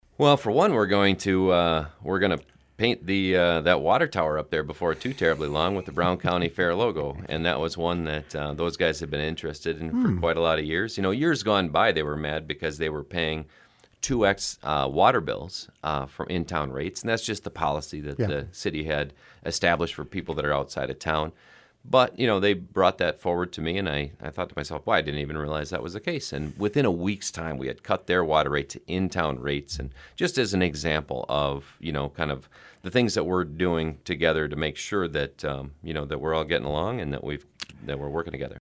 On Wednesday he appeared on the KSDN Midday Report to talk more about that.